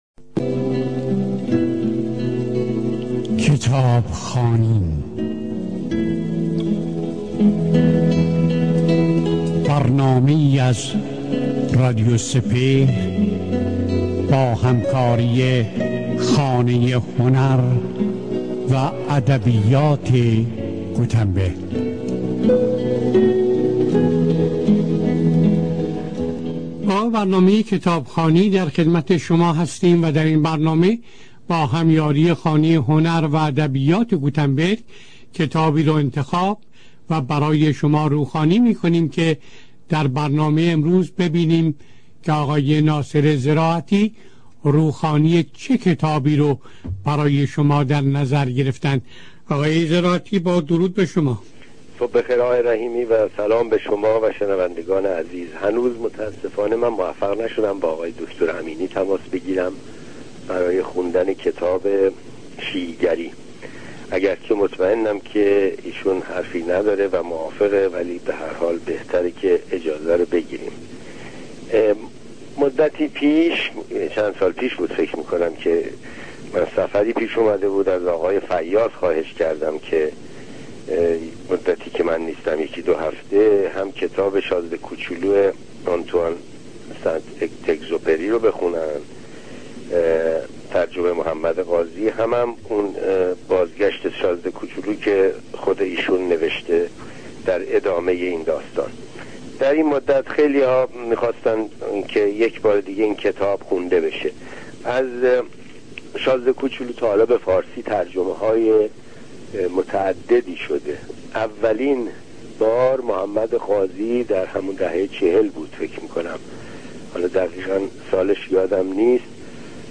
روخوانی